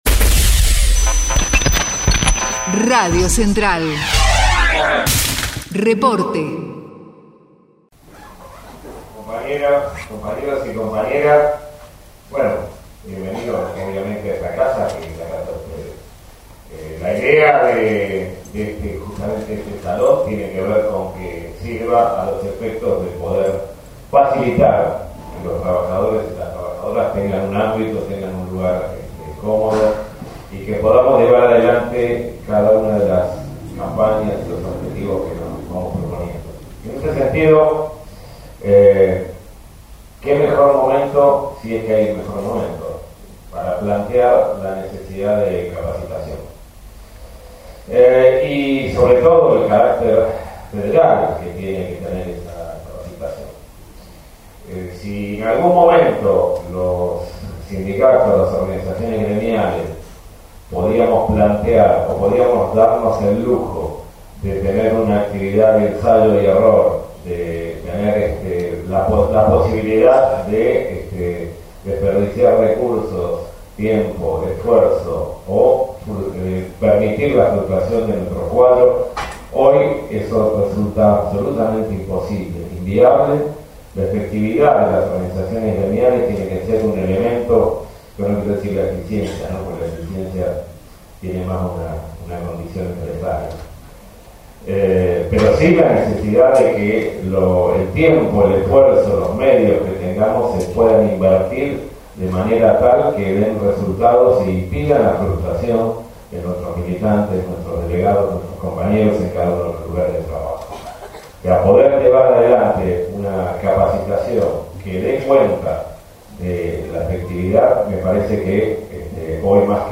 ESCUELA DE FORMACIÓN SINDICAL "EDUARDO BASUALDO" - Testimonio